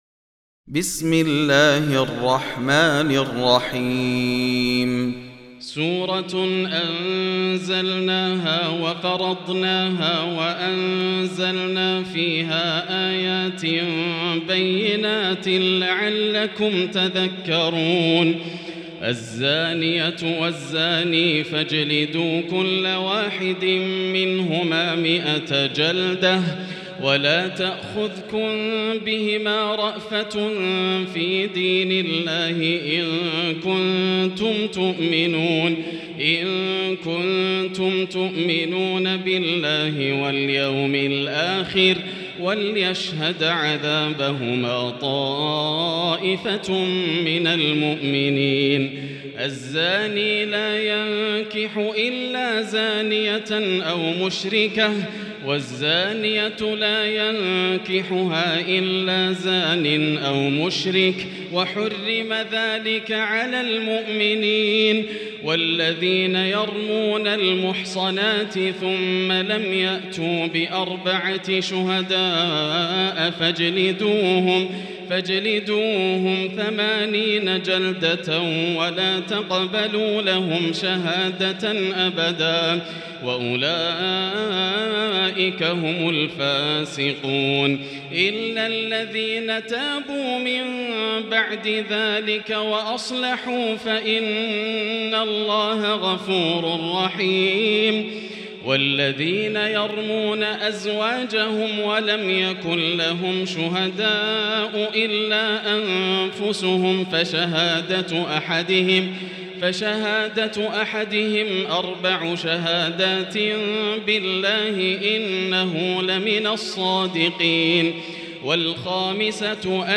المكان: المسجد الحرام الشيخ: فضيلة الشيخ ياسر الدوسري فضيلة الشيخ ياسر الدوسري النور